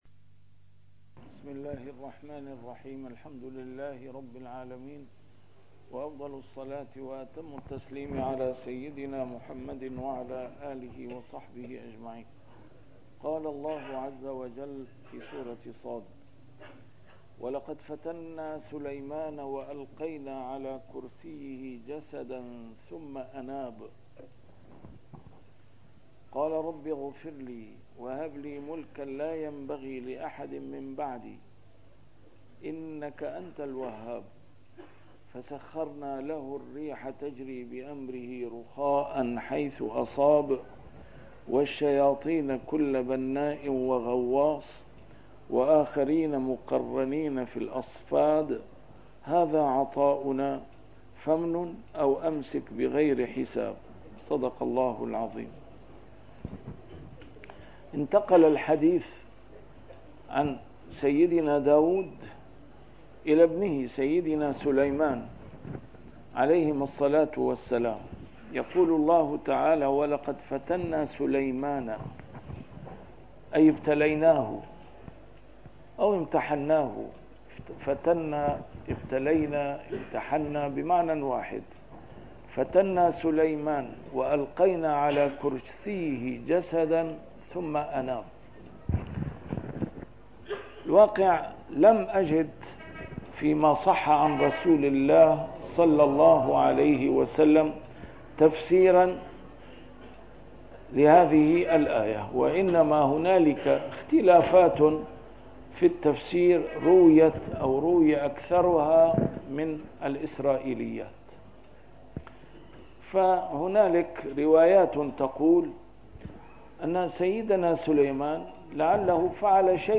نسيم الشام › A MARTYR SCHOLAR: IMAM MUHAMMAD SAEED RAMADAN AL-BOUTI - الدروس العلمية - تفسير القرآن الكريم - تسجيل قديم - الدرس 470: ص 34-40